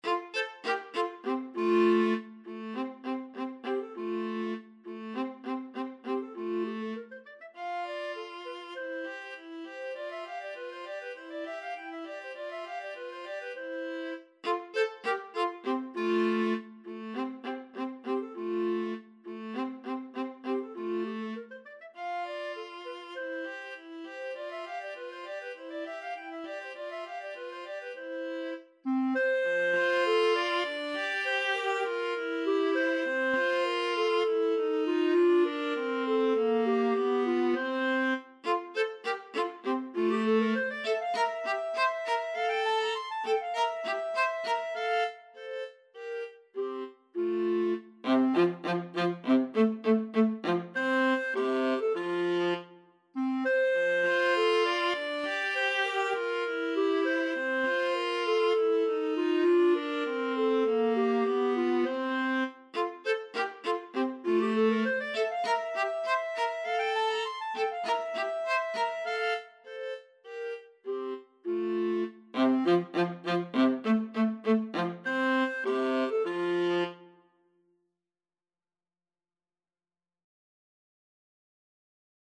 2/4 (View more 2/4 Music)
Classical (View more Classical Clarinet-Viola Duet Music)